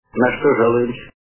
» Звуки » Люди фразы » Из к/ф - На что залуешься
При прослушивании Из к/ф - На что залуешься качество понижено и присутствуют гудки.